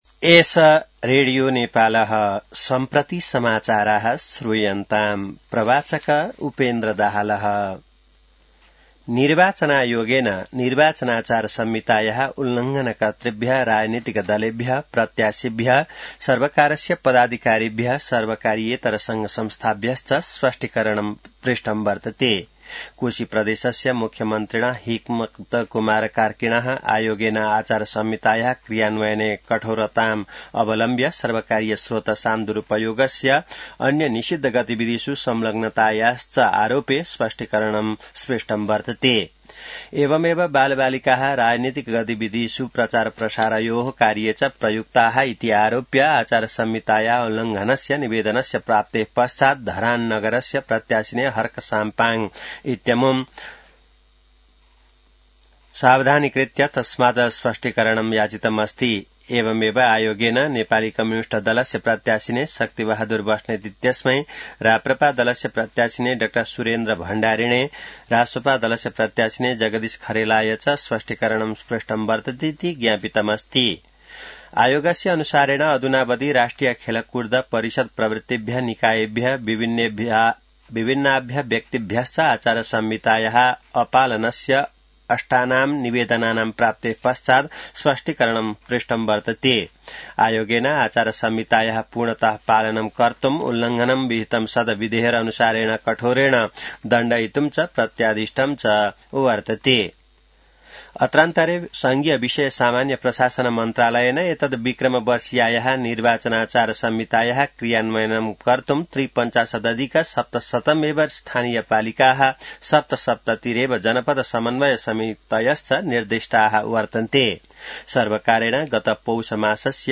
संस्कृत समाचार : १३ माघ , २०८२